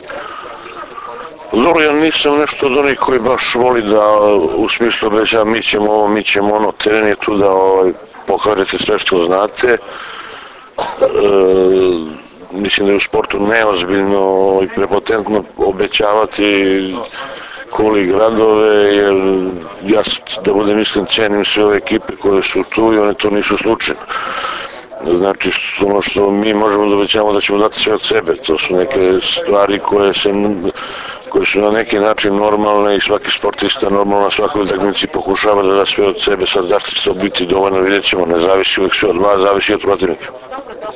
U pres sali SD Crvena zvezda danas je održana konferencija za novinare povodom Finalnog turnira 46. Kupa Srbije u konkurenciji odbojkaša.
IZJAVA